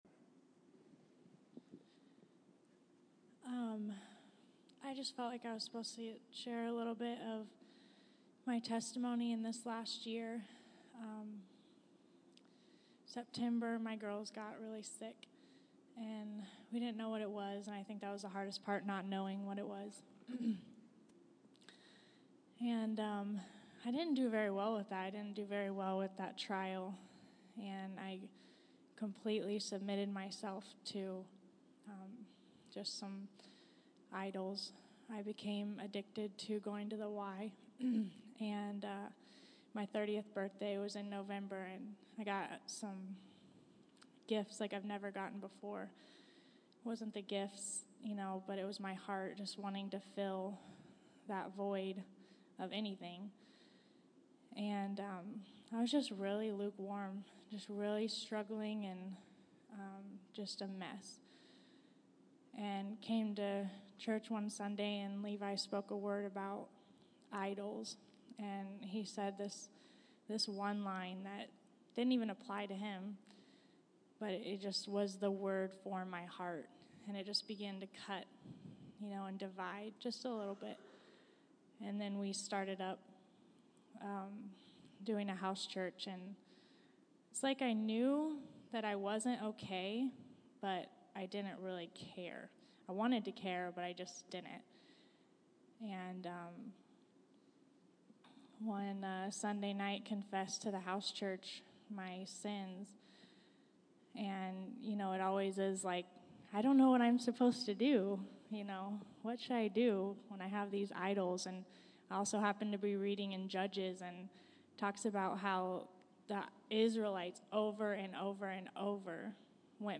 Category: Testimonies